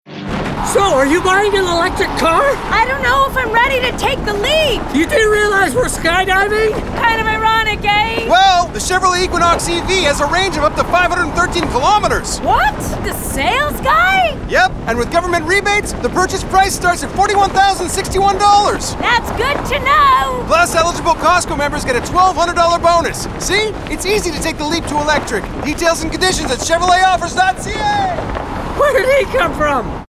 Commercial (Equinox) - EN